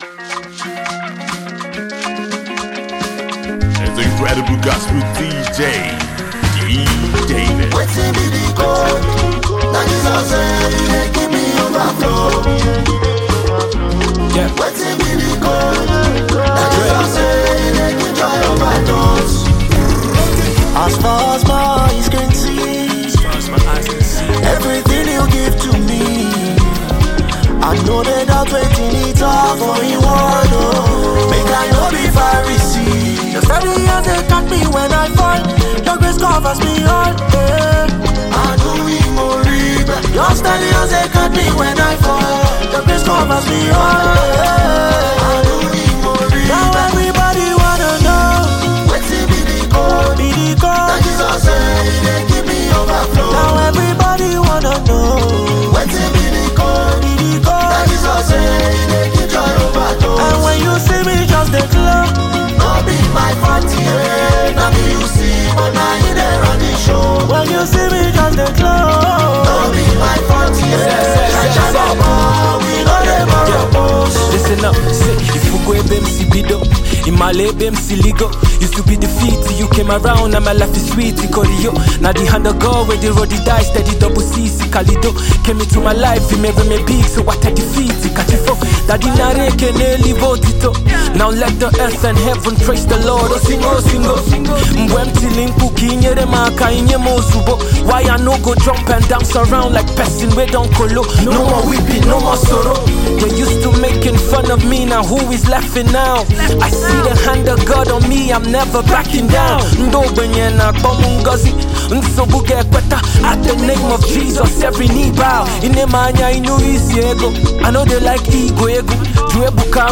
the vibrant, pulse-driving energy of Afro-Gospel
danceable vibe can be one and the same.